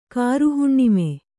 ♪ kāruhuṇṇimi